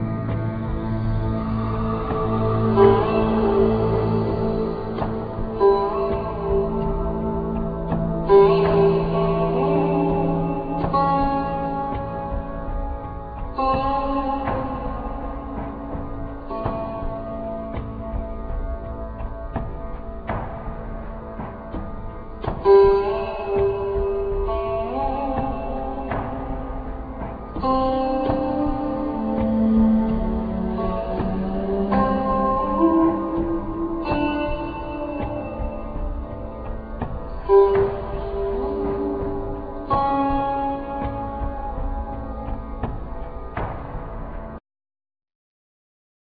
African drums
Indian drums
Accordion,Keyboards
Sitar,Surbahar,Keyboards